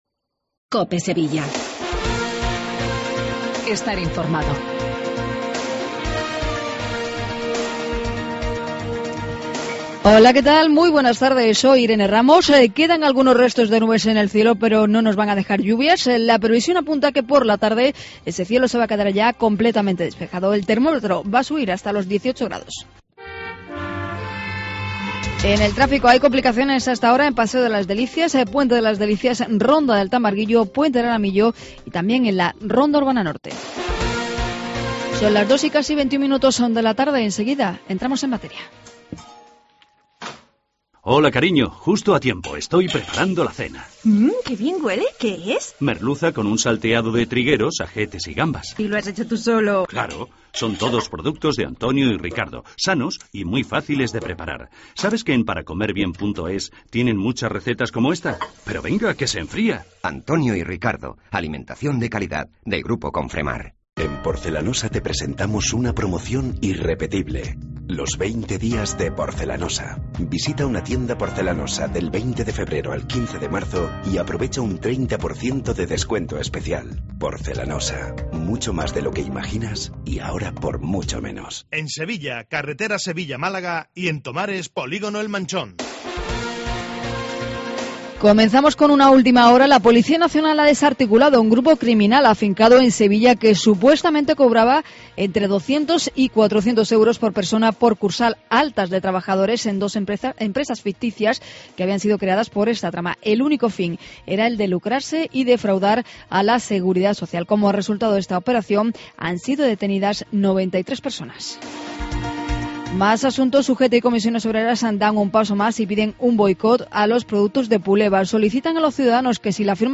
INFORMATIVO LOCAL DEL MEDIODIA DE COPE SEVILLA